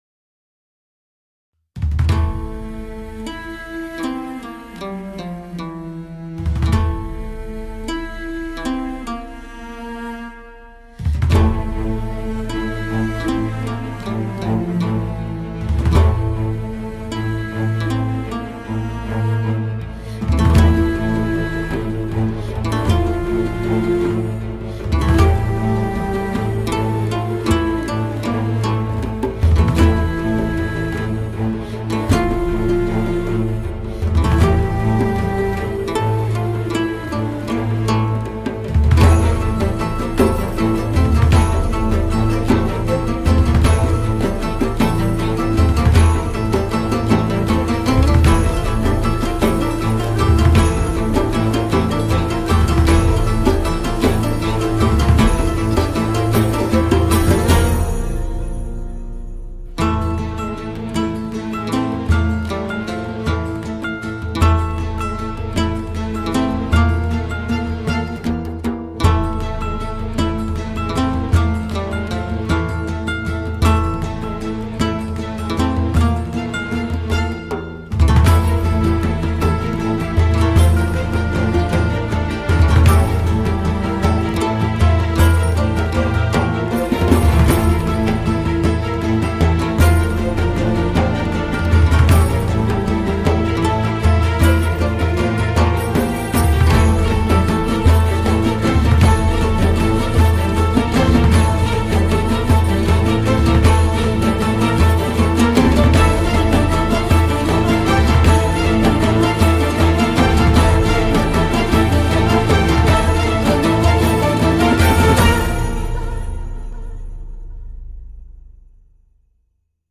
tema dizi müziği, duygusal gerilim heyecan fon müziği.